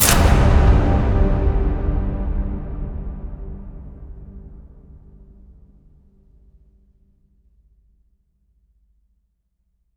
LC IMP SLAM 6A.WAV